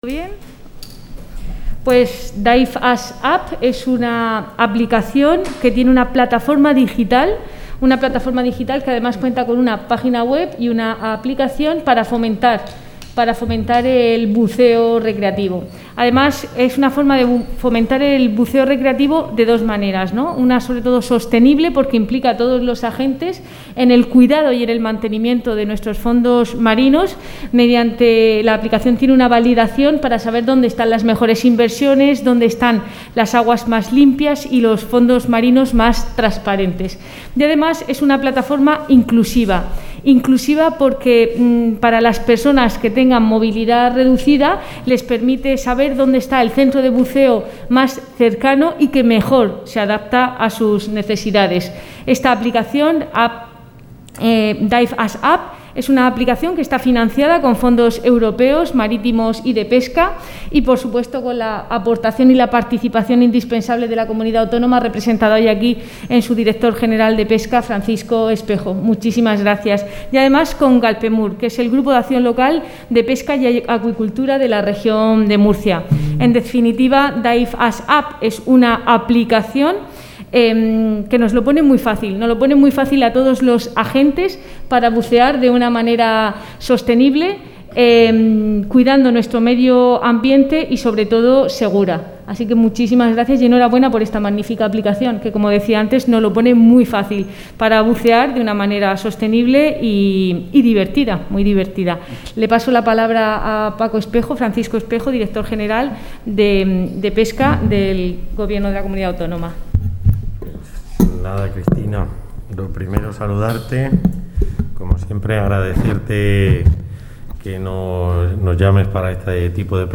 Audio: Presentaci�n de la aplicaci�n Diveasapp (MP3 - 31,02 MB)